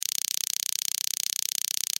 tase.ogg